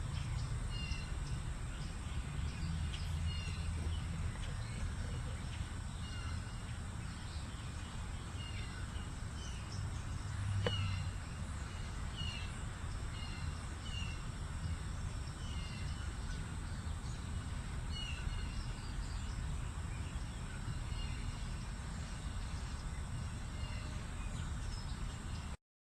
Melanerpes carolinusRed-bellied WoodpeckerCarpintero de CarolinaPic à ventre roux